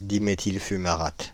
Ääntäminen
Synonyymit fumarate de diméthyle DMFu Ääntäminen France (Île-de-France): IPA: /di.me.til fy.ma.ʁat/ Haettu sana löytyi näillä lähdekielillä: ranska Käännös Konteksti Substantiivit 1. dimethyl fumarate kemia 2.